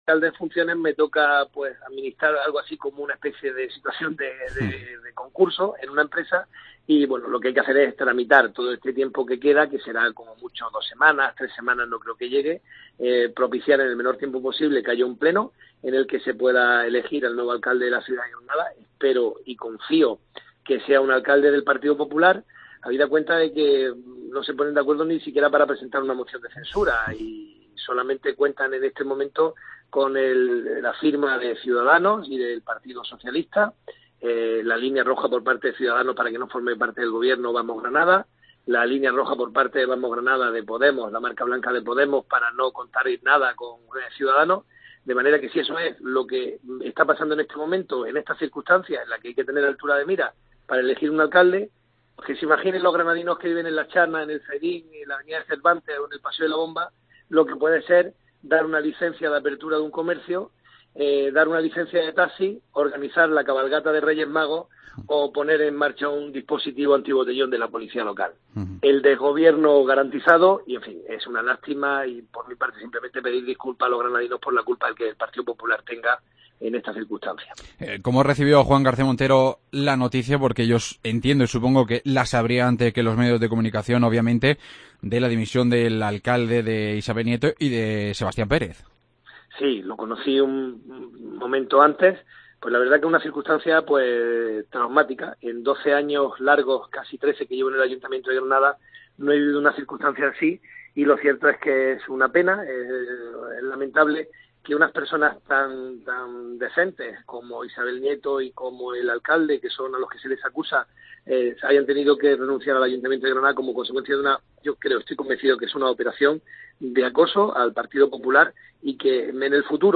Entrevista a Juan García Montero, alcalde en funciones de Granada